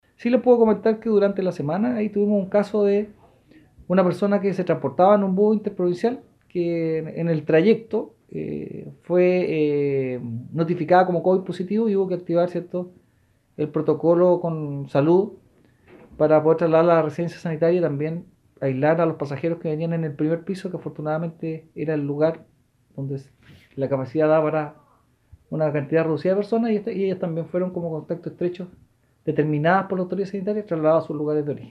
El oficial de la policía uniformada relató además un caso que ocurrió en la última semana del mes de enero, se debió activar el respectivo protocolo en un minibús que hacia el recorrido hacia Ancud, luego que un pasajero fue notificado en viaje, que era un caso positivo.